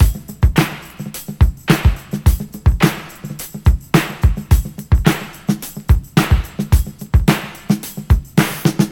108 Bpm 80's Drum Loop G# Key.wav
Free breakbeat sample - kick tuned to the G# note. Loudest frequency: 986Hz